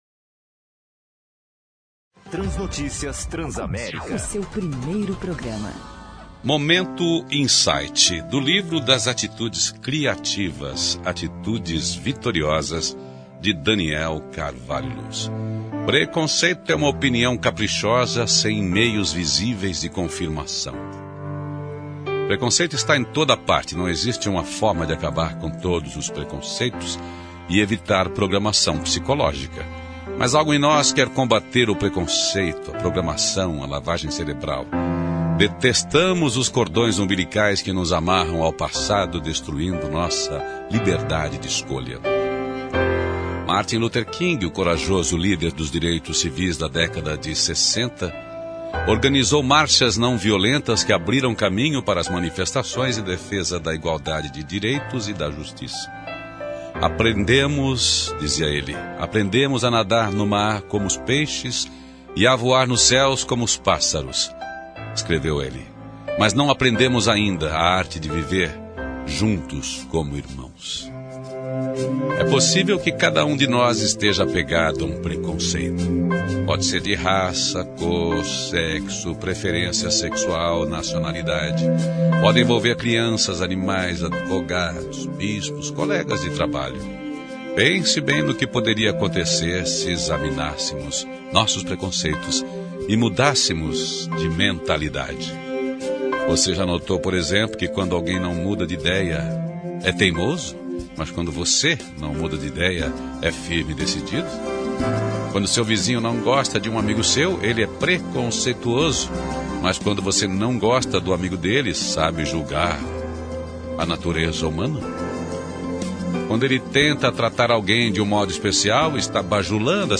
Trecho do programa Transnotícias na rádio Transamérica de 17 de Outubro de 2011.